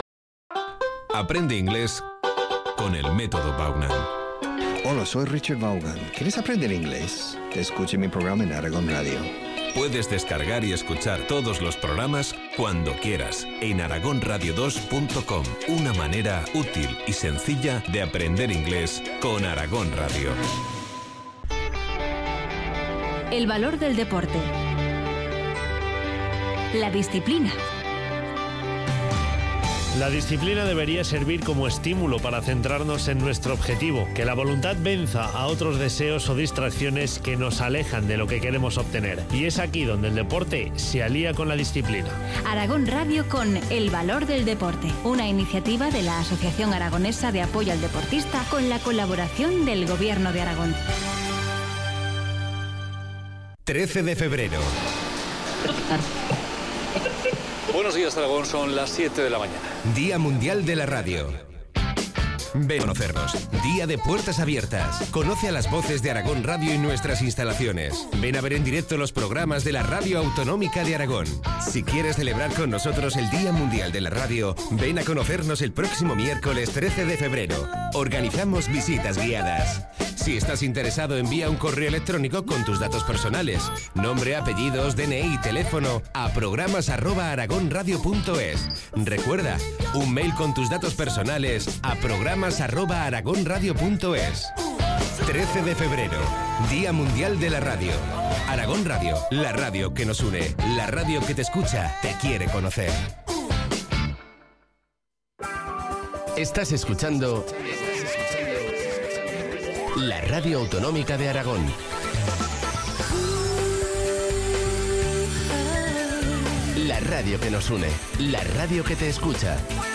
Hablamos de la llegada del inminente día de San Valentín el próximo 14 de Febrero: ¿es necesario celebrarlo?¿cómo meter la pata o cómo acertar?¿cuáles son los mejores regalos?… Mi intervención es desde el minuto 9.50 al 16.45…